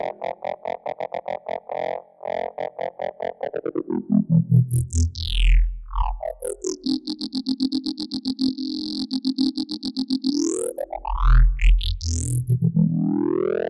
Tag: 140 bpm Dubstep Loops Bass Wobble Loops 2.31 MB wav Key : Unknown